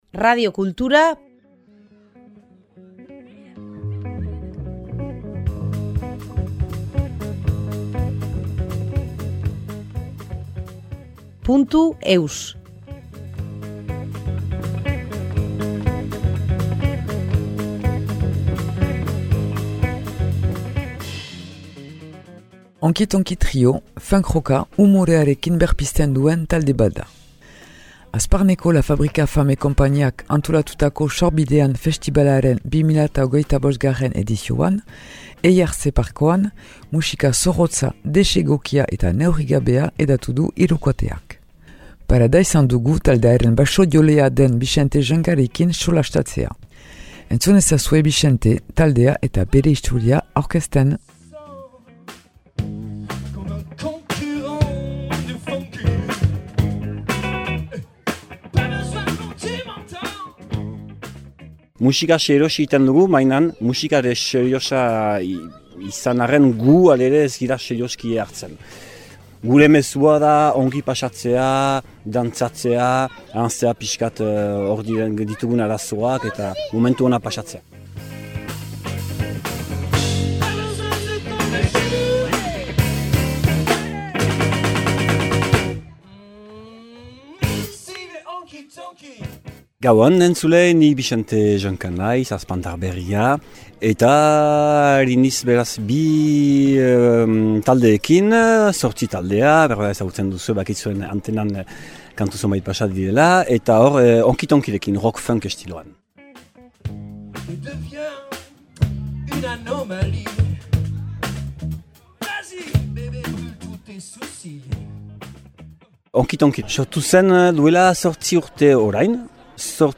Honky Tonky trio, funck rocka umorearekin berpizten duen talde bat da. Hazparneko « La Fabrique Affamée » konpaniak antolatutako Sorbidean festibalaren 2025. edizioan, Eihartzea parkoan, musika zorrotza, desegokia eta neurrigabea hedatu du hirukoteak.